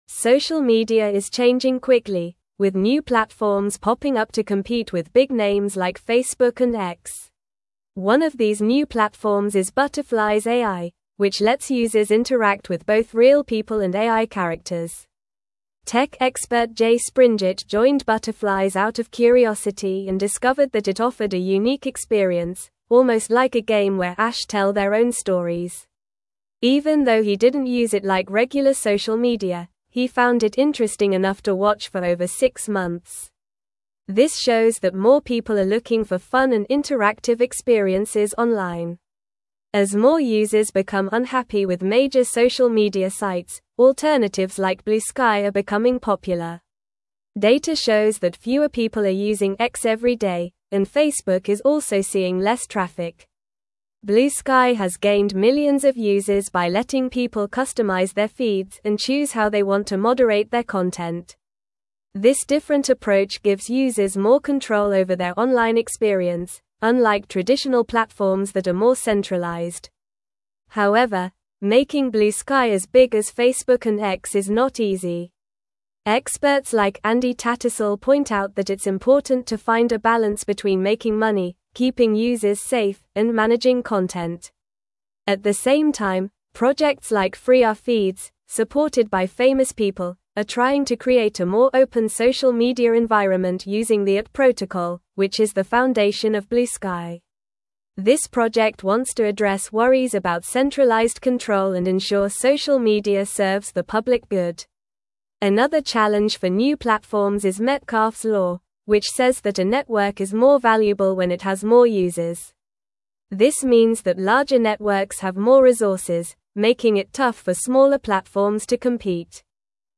Normal
English-Newsroom-Upper-Intermediate-NORMAL-Reading-Emerging-Social-Media-Platforms-Challenge-Established-Giants.mp3